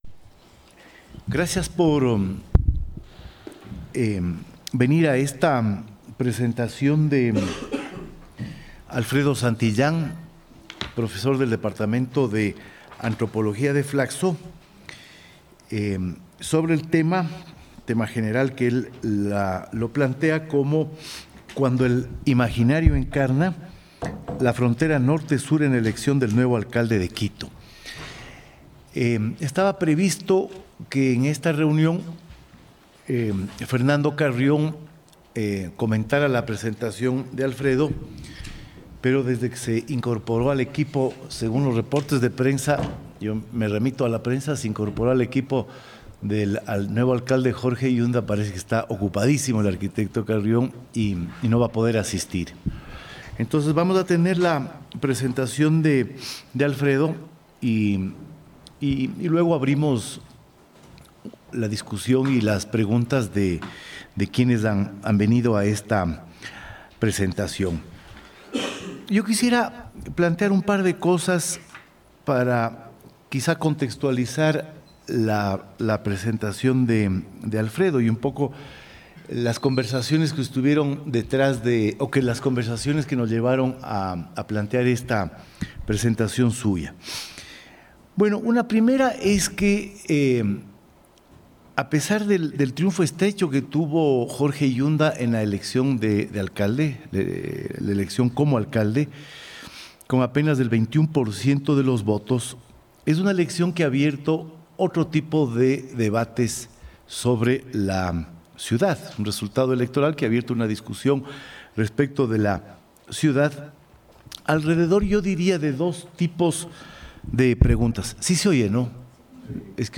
Debates Académicos - Flacso Ecuador